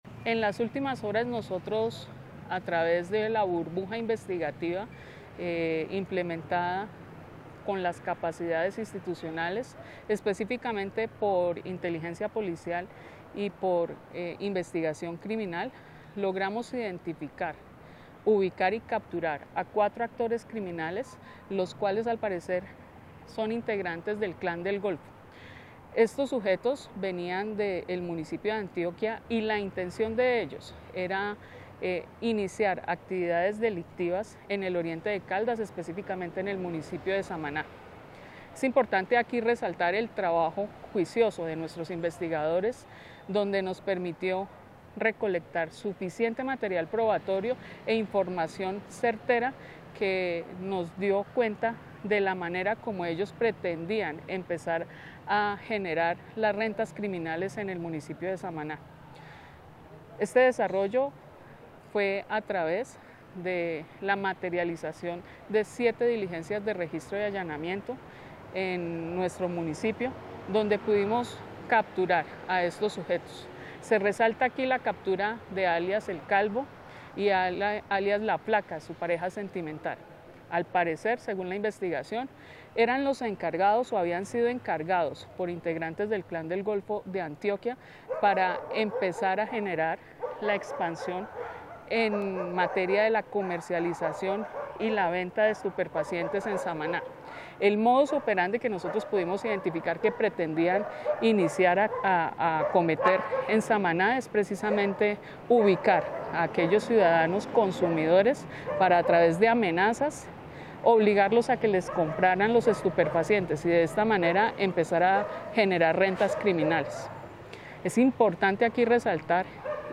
Coronel Liliana Jiménez Falla, Comandante de Policía Caldas.